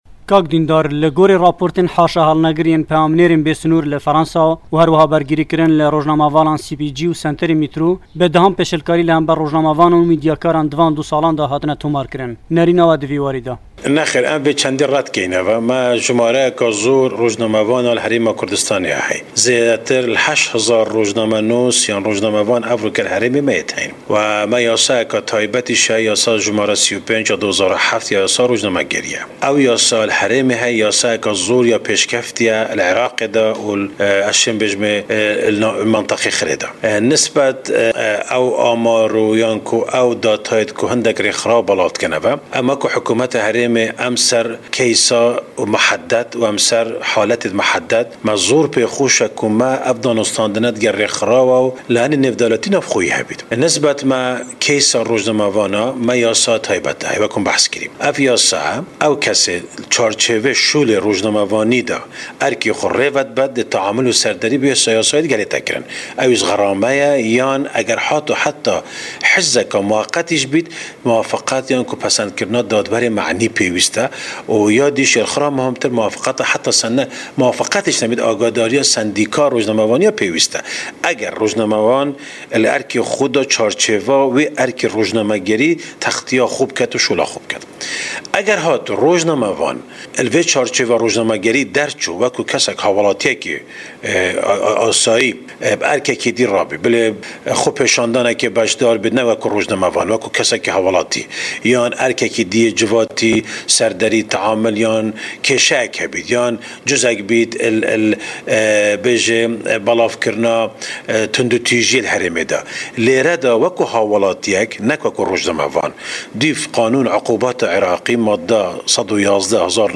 وتووێژ لەگەڵ دیندار زێباری